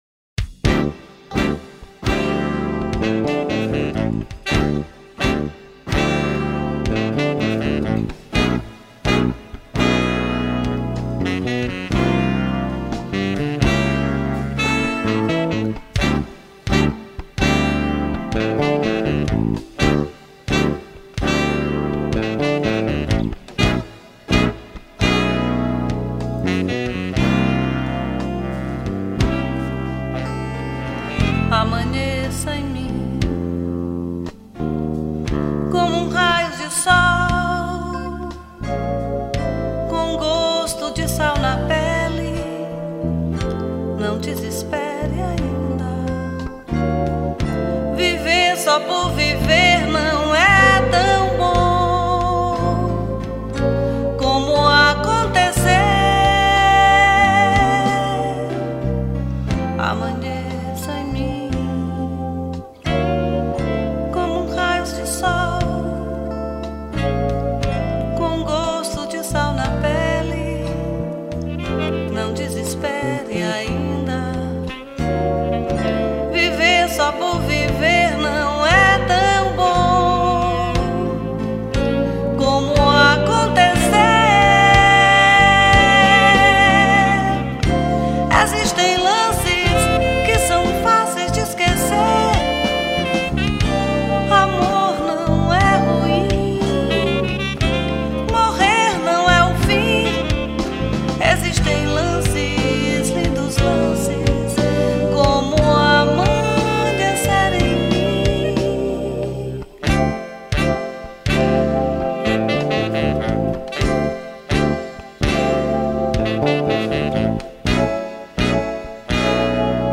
2934   03:53:00   Faixa: 1    Mpb
Baixo Elétrico 6
Piano Acústico, Teclados
Guitarra
Bateria
Flauta